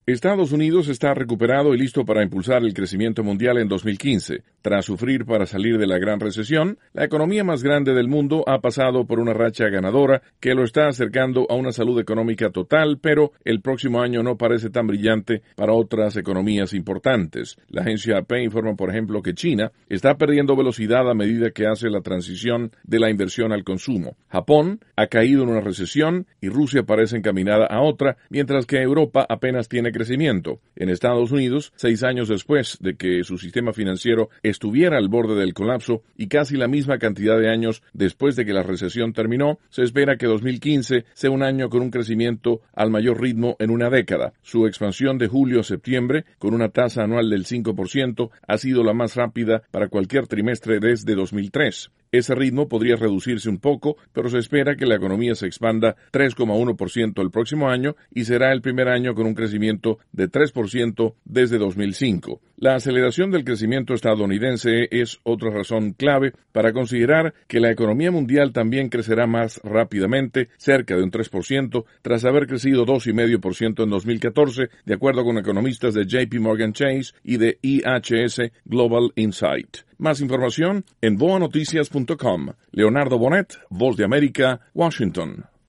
con más detalles desde Washington